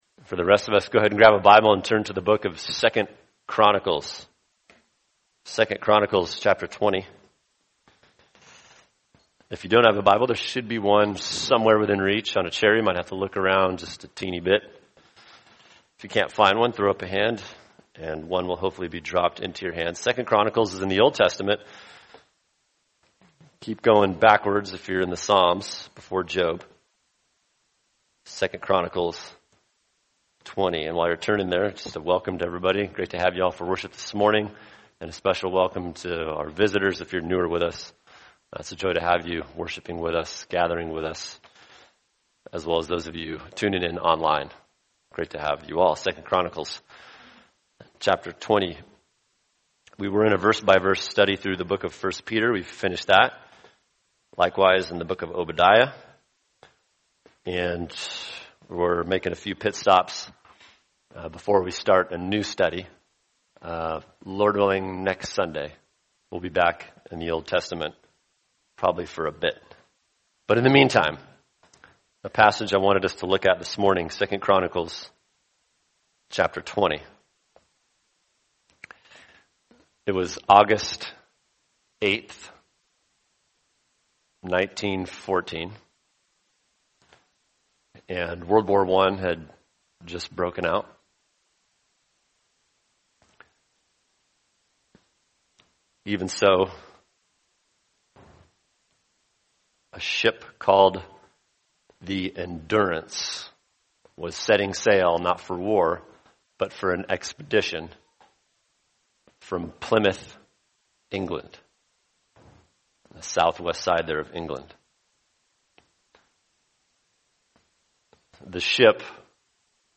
[sermon] 2 Chronicles 20 What To Do When You Don’t Know What To Do | Cornerstone Church - Jackson Hole